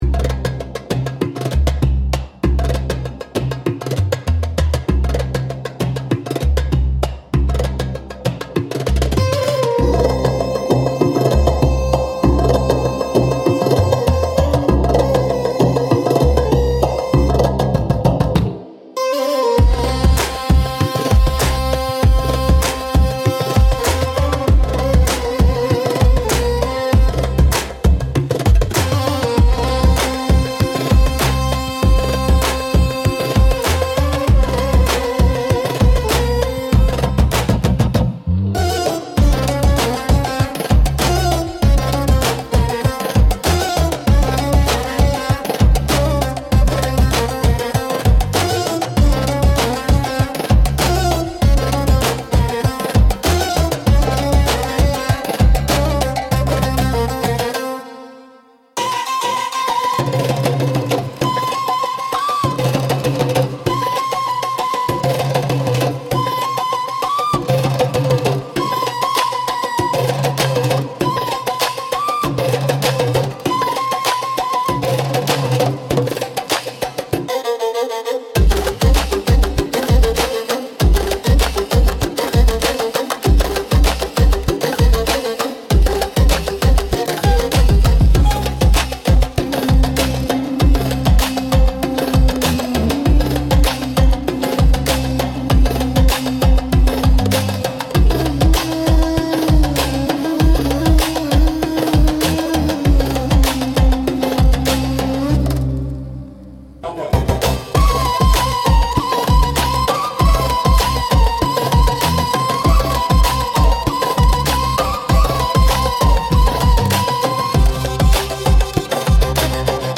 Instrumental - Ancient Shadows Speak